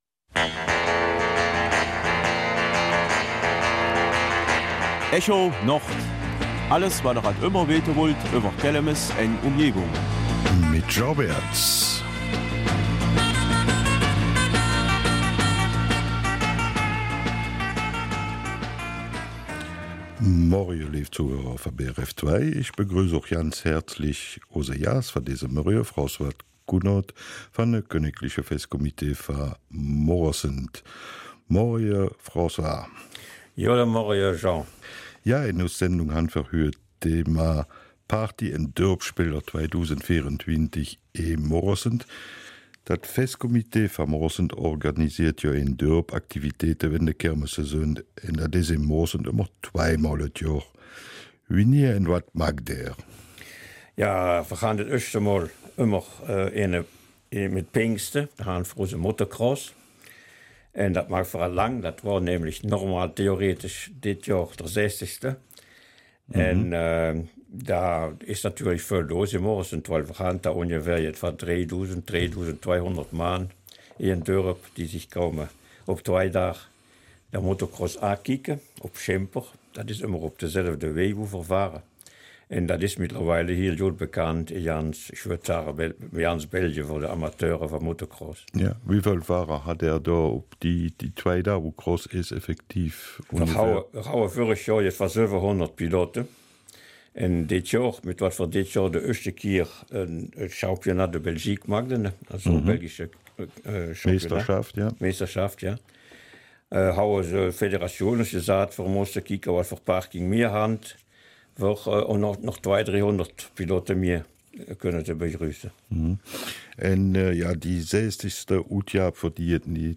Kelmiser Mundart: Kirmes-Partys und Viertelspiele 2024 in Moresnet